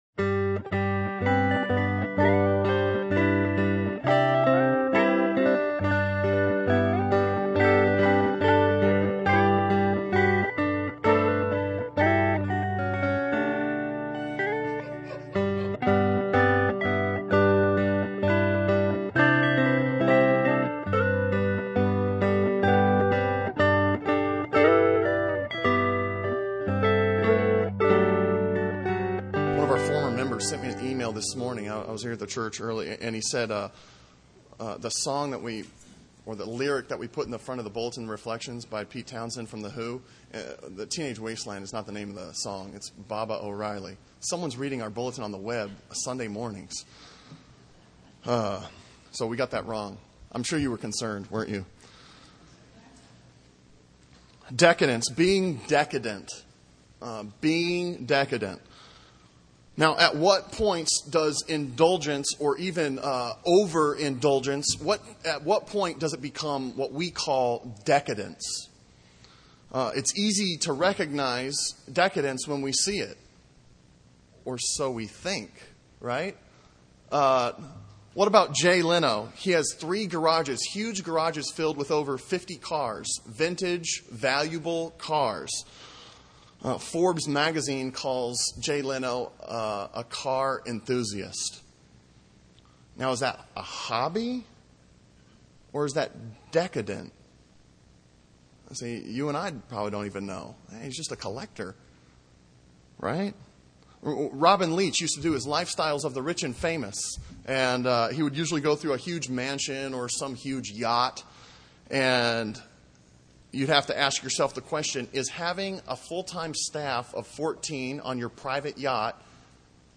Sermon on Hosea 7:3-16 from July 20